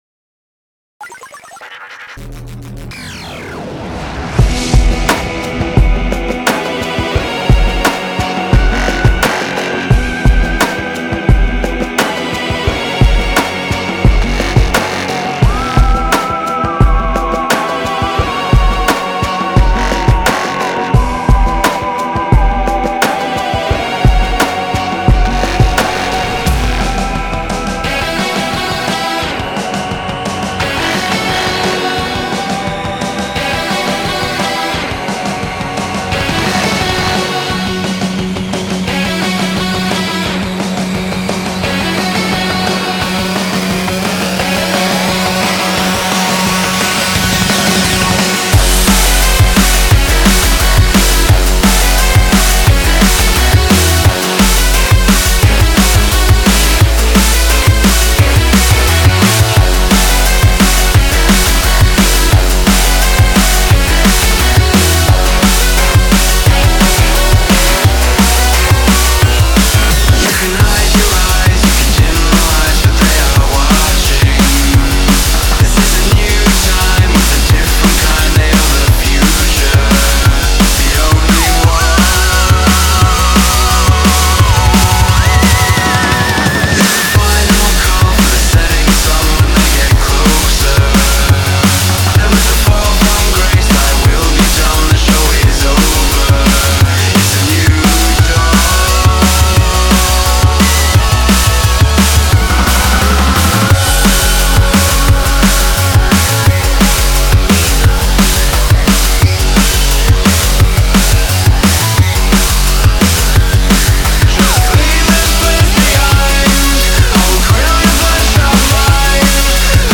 Категория: Drum and Bass